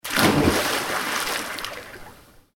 Splash.wav